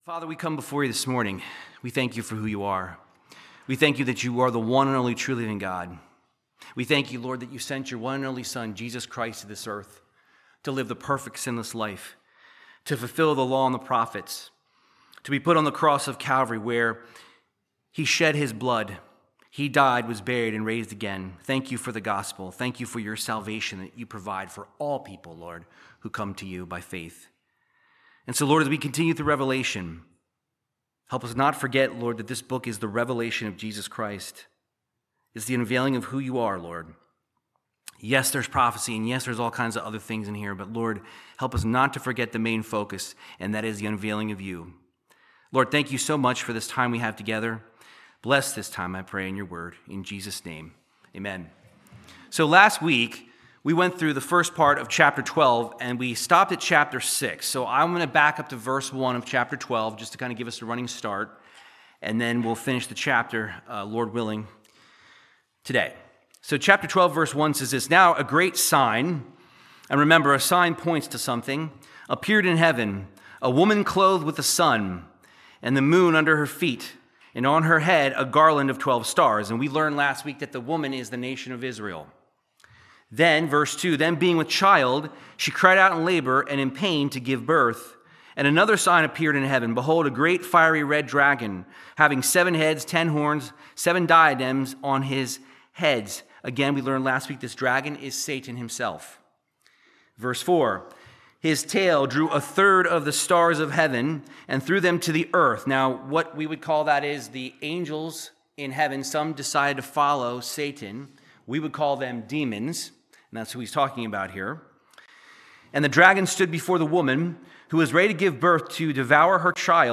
Verse by verse Bible teaching on Revelation chapter 12 verses 7 through 17